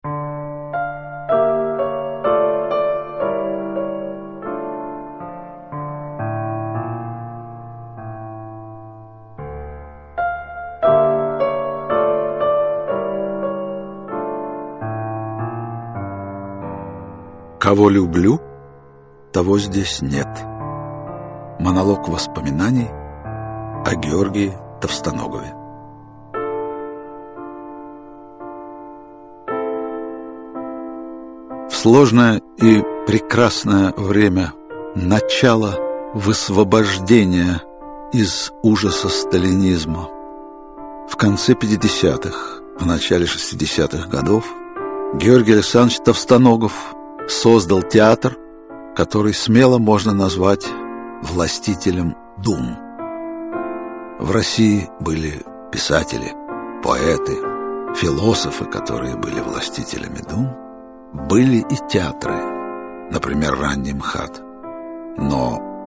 Георгий Товстоногов Автор Сергей Юрский Читает аудиокнигу Сергей Юрский.